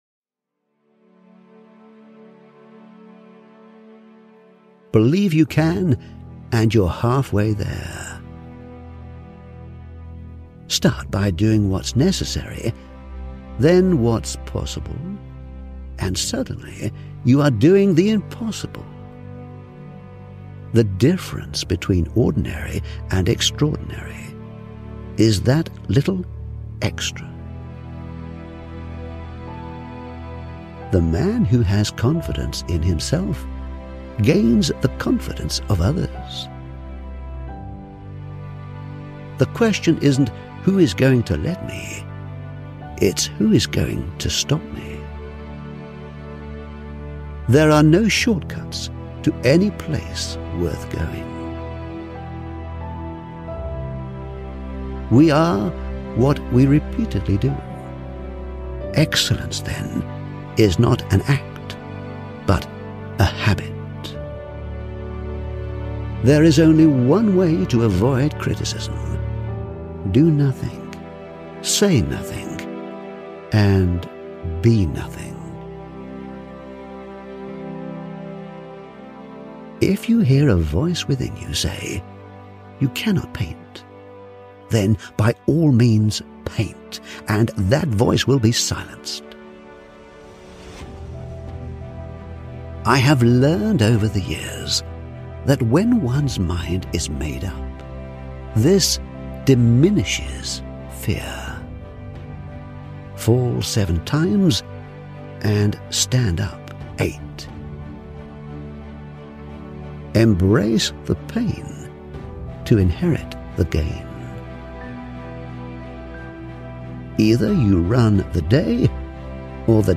Male
English (British)
Adult (30-50), Older Sound (50+)
A full-time British voice over artist, with over 18 years experience.
0223HARD_WORK_QUOTES_TO_SUCCESS__Motivational_Video.mp3